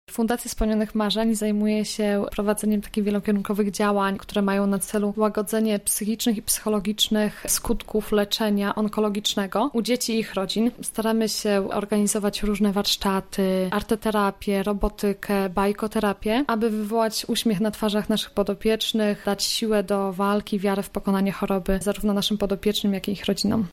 Czym zajmuje się fundacja? O tym mówi wolontariuszka